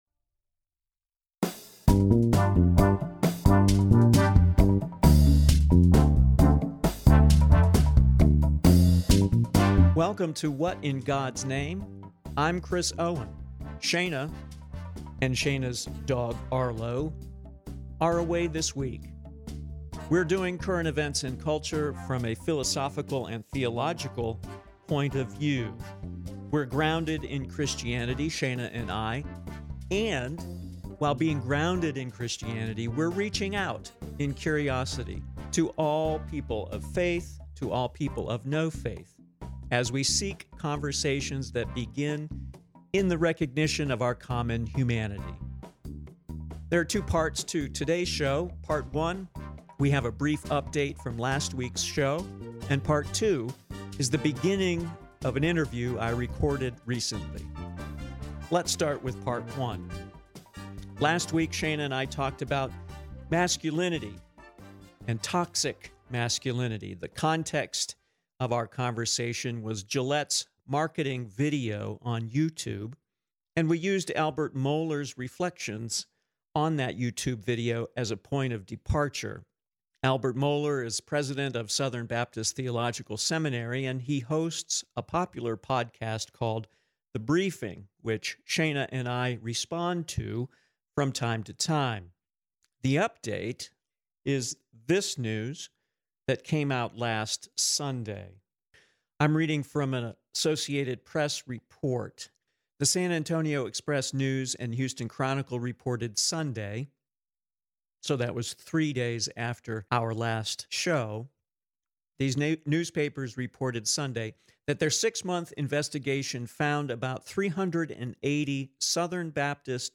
Join us in conversation.